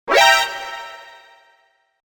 metal-gear-alert-sound-effect.mp3